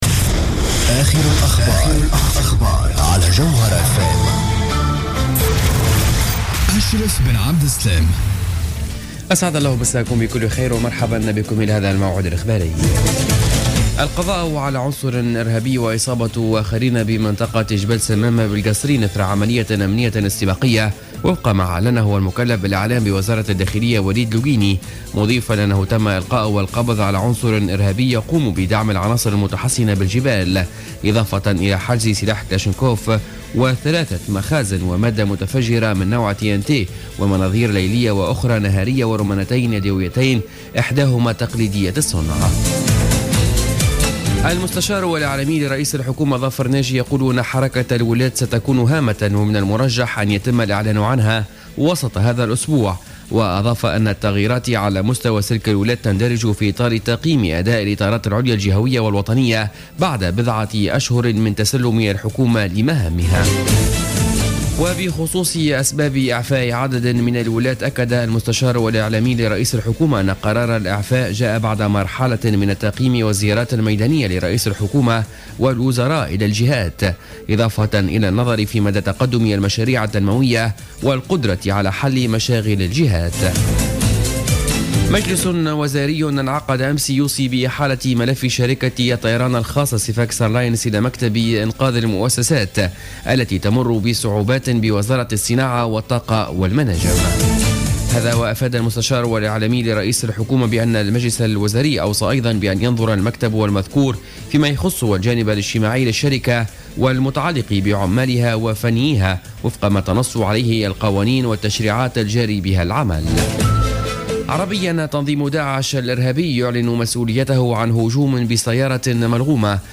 نشرة أخبار منتصف الليل ليوم الثلاثاء 11 أوت 2015